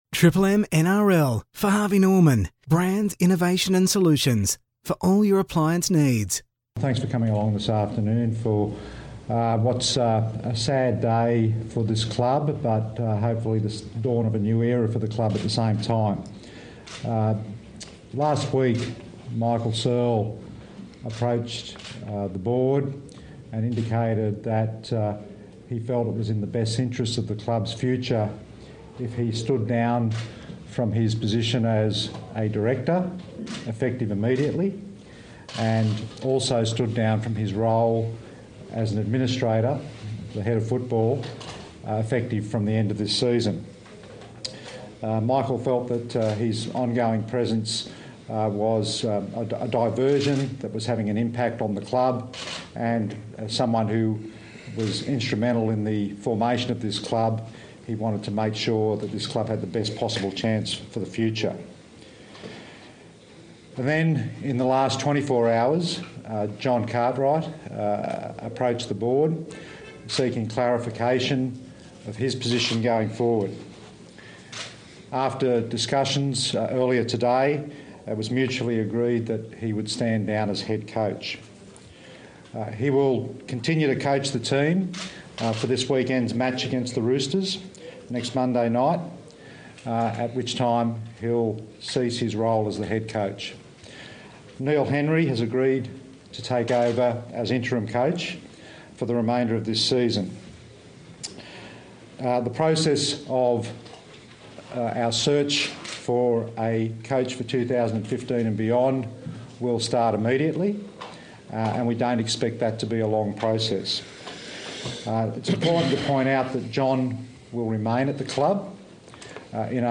Titans John Cartwright Press Conference